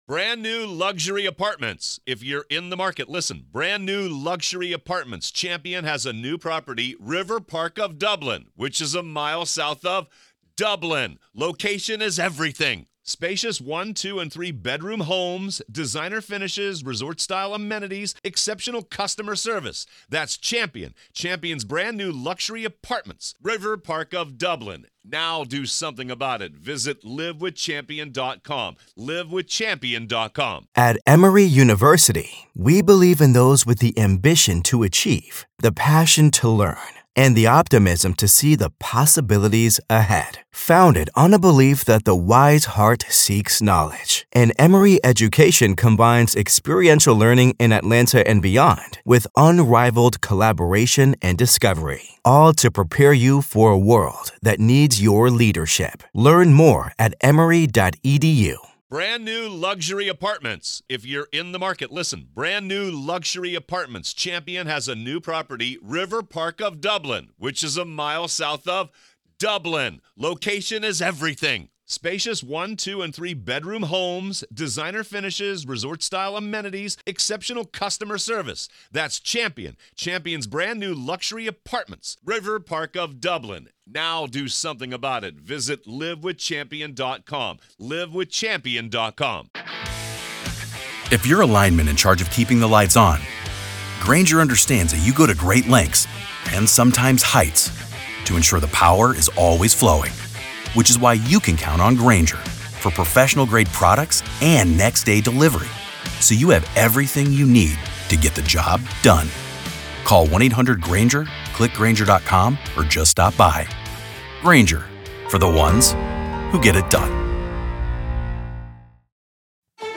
The Trial Of Alex Murdaugh | FULL TRIAL COVERAGE Day 16 - Part 3